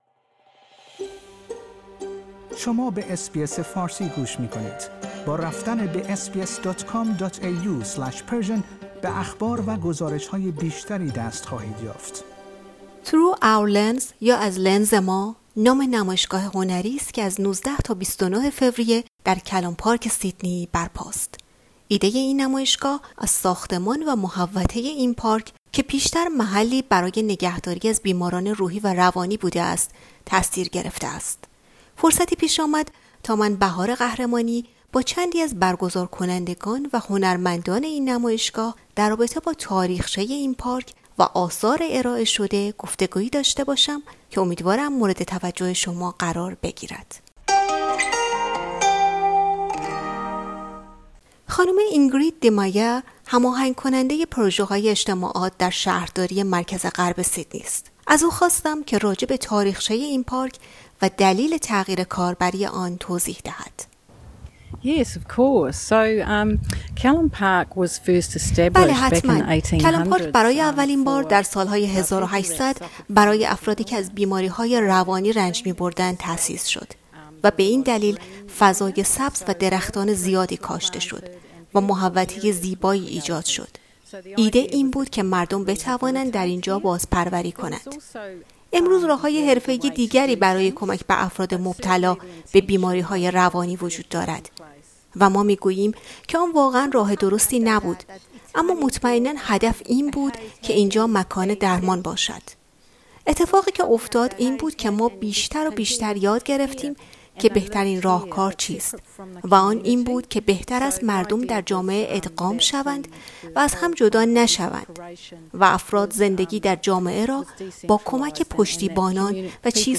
ایده این نمایشگاه از ساختمان و محوطه این پارک که پیشتر محلی برای نگهداری از بیماران روحی و روانی بوده است، تاثیر گرفته است. فرصتی پیش آمد تا با چندی از برگزارکنندگان و هنرمندان این نمایشگاه در رابطه با تاریخچه این پارک و آثار ارائه شده در آن، گفتگویی داشته باشیم.